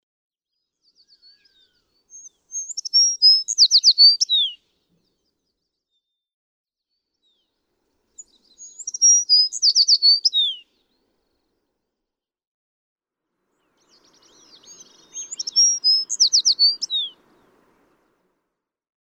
American tree sparrow
♫275—one song from each of three individuals
275_American_Tree_Sparrow.mp3